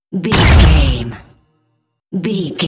Dramatic hit laser
Sound Effects
Atonal
heavy
intense
dark
aggressive